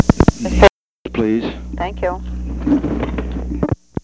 On October 6, 1971, President Richard M. Nixon and the White House operator talked on the telephone at 8:00 pm. The White House Telephone taping system captured this recording, which is known as Conversation 010-102 of the White House Tapes.
The President talked with the White House operator.